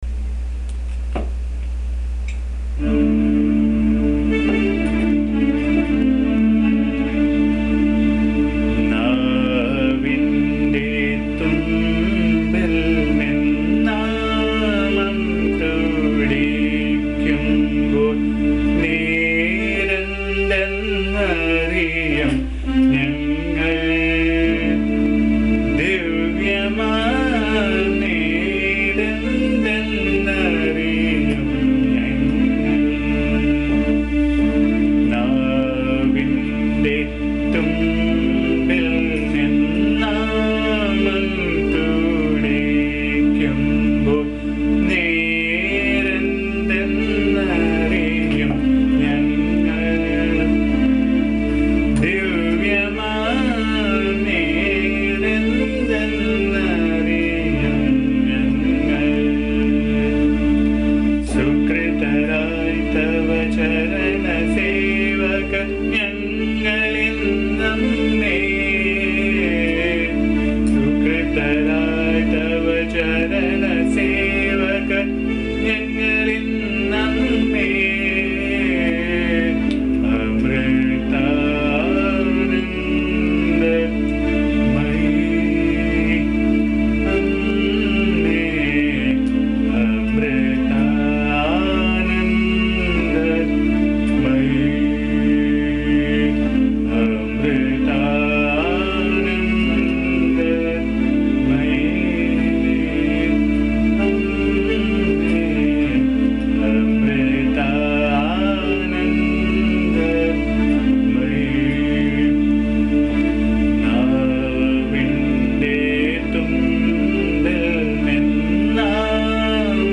The song sung in my voice can be found here.
bhajan song